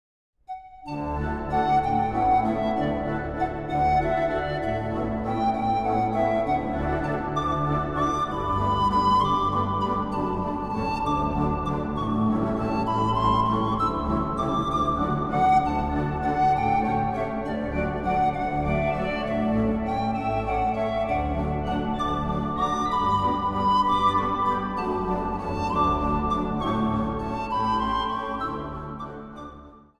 De leukste kinderliedjes
panfluit
orgel.